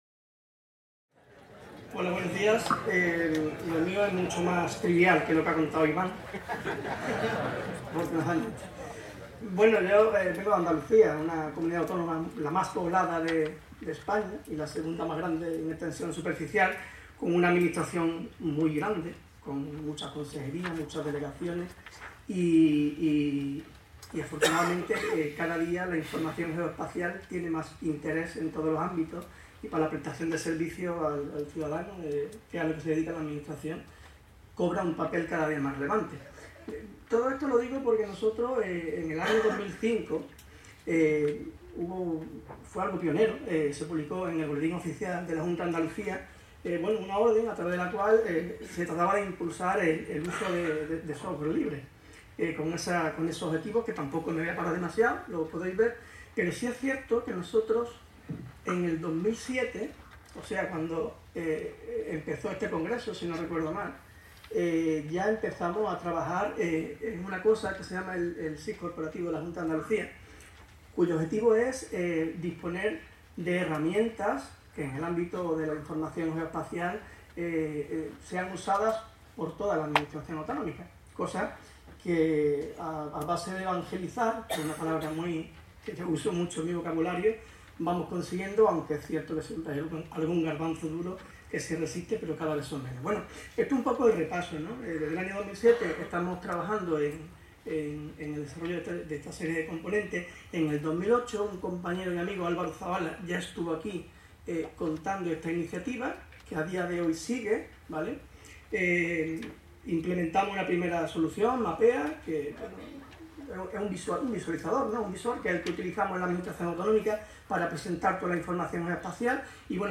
en el marc de les 18enes Jornades de SIG Lliure 2025 organitzades pel SIGTE de la Universitat de Girona. En aquesta presentació s'explica l'evolució del sistema d'informació geogràfica que utilitza la Junta de Andalucía i els projectes i reptes de futur que aborden